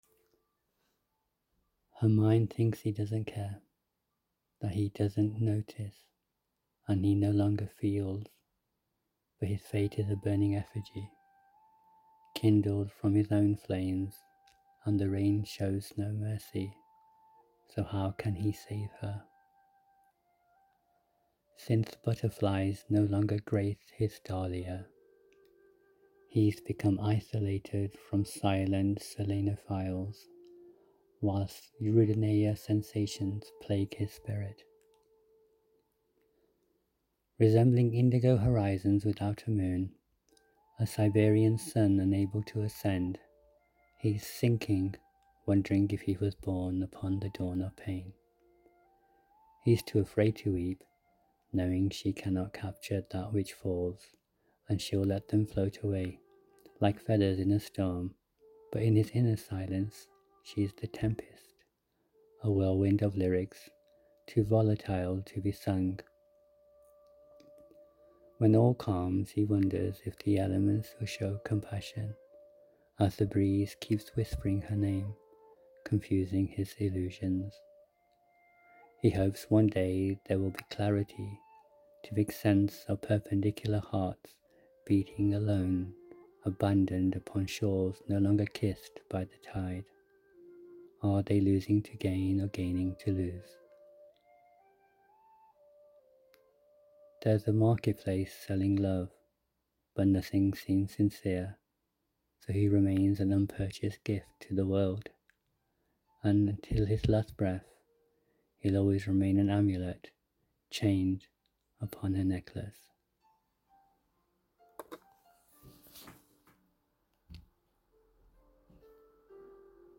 Your reading my friend also was relaying these words in your emotional tones in your inflections in speech.